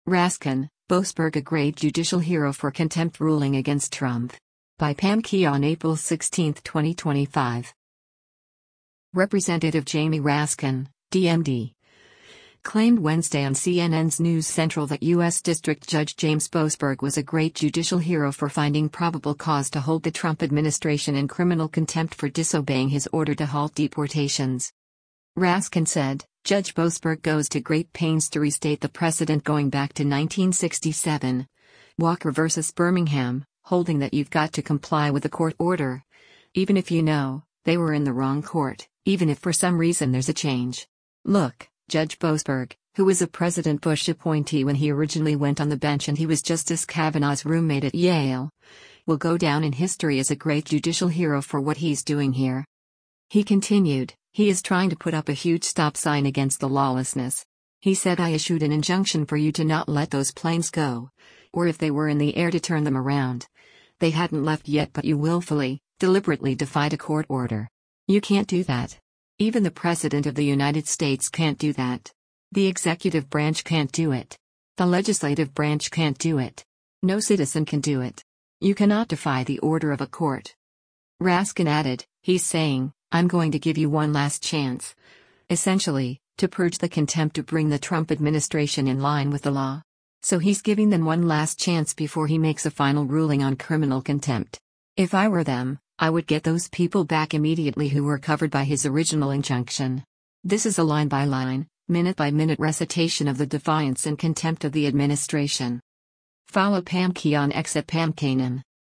Representative Jamie Raskin (D-MD) claimed Wednesday on CNN’s “News Central” ” that U.S. District Judge James Boasberg was a “great judicial hero” for finding probable cause to hold the Trump administration in criminal contempt for disobeying his order to halt deportations.